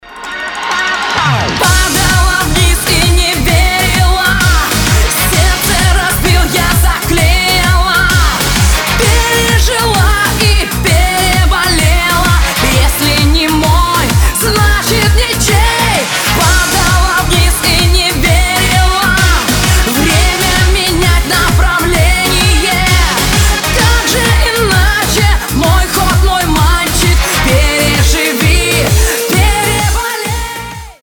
поп
громкие